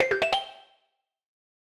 newNotif01.ogg